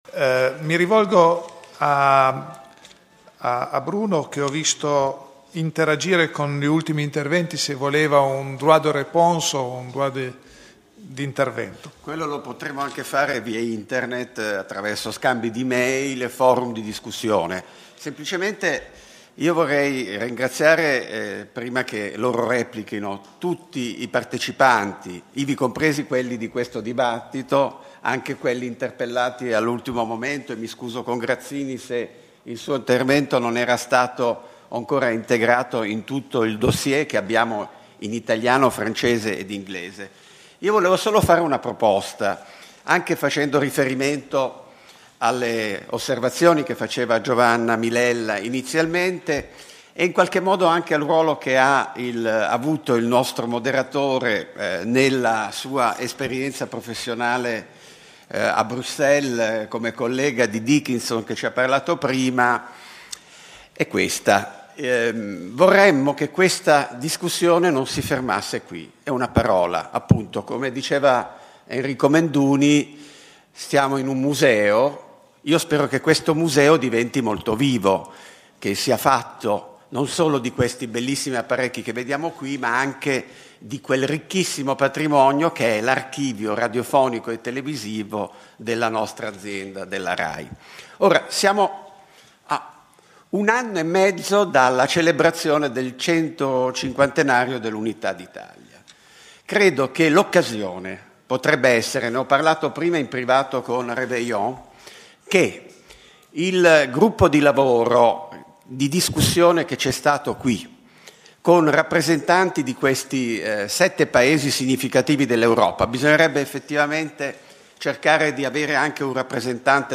Turin, Rai - Prix Italia – 24 settembre 2009
DÉBATE